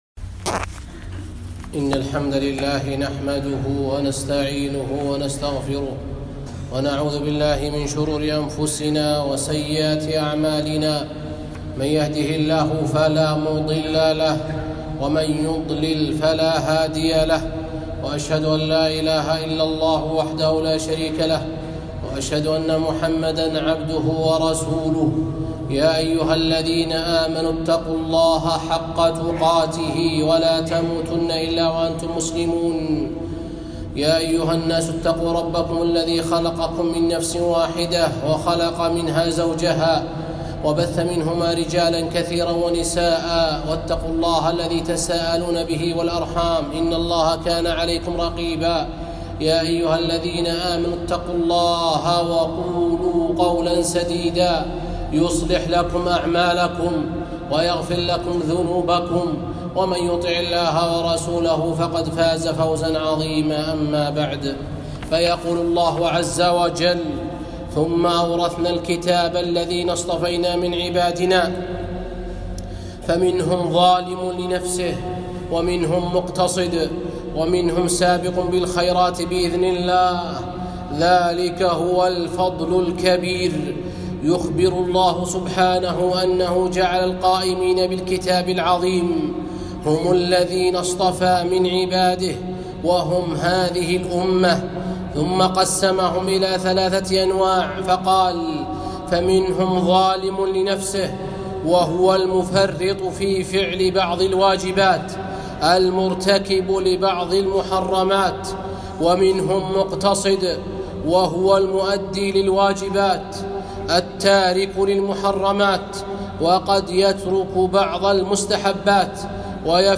خطبة - الحمدلله الذي أذهب عنا الحزن